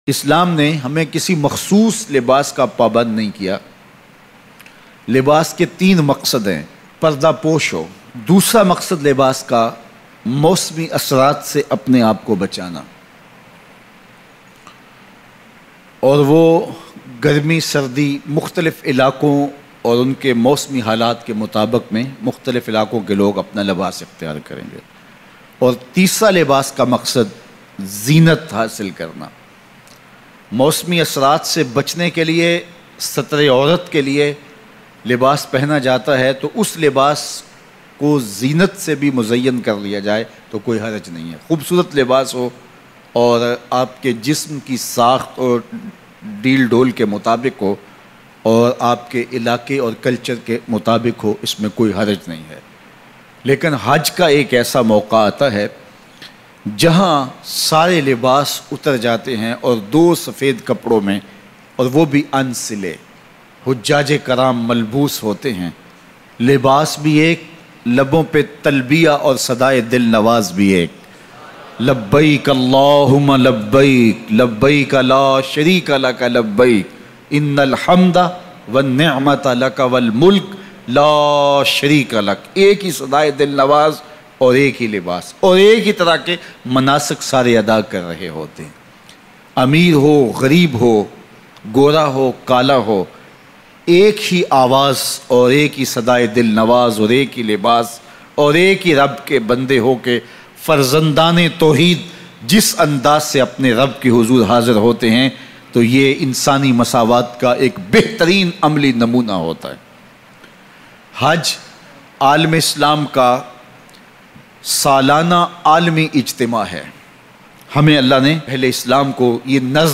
Bayan MP3